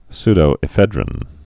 (sdō-ĭ-fĕdrĭn, -ĕfĭ-drēn)